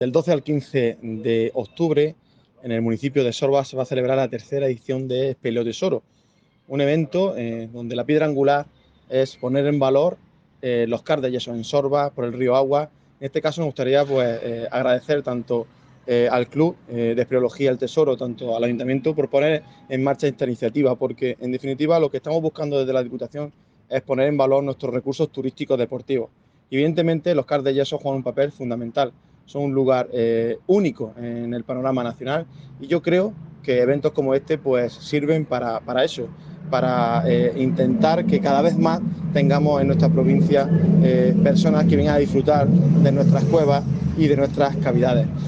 06-10_sorbas_diputado.mp3